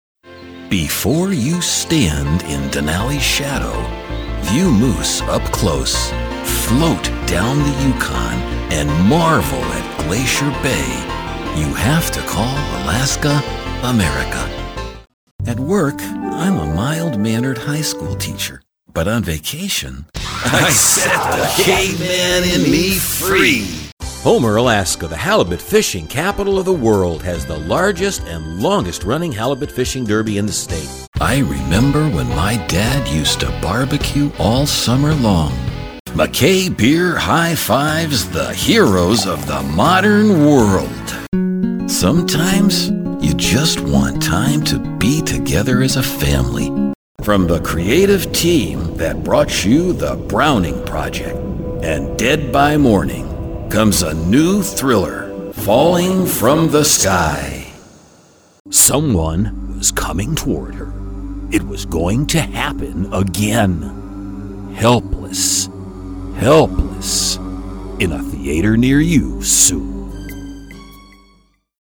Sprechprobe: Werbung (Muttersprache):
Confident, absolutely believable